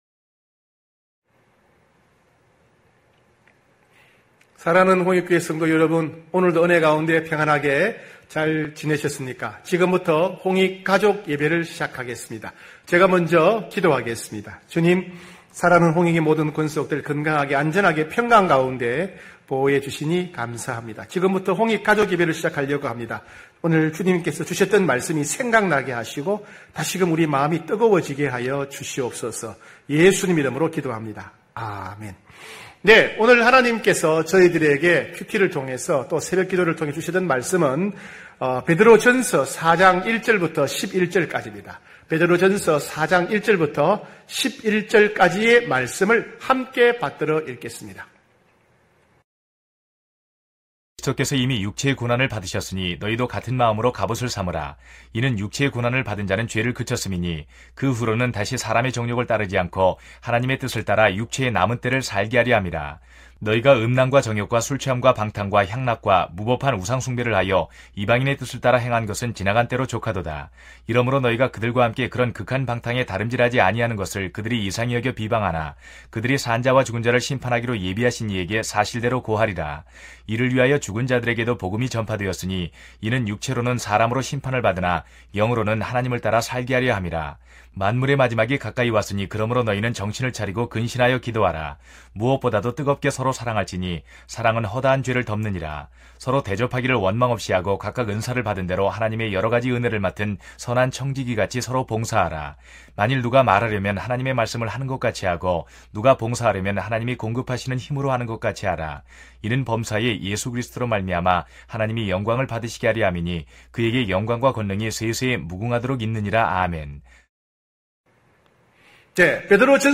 9시홍익가족예배(7월7일).mp3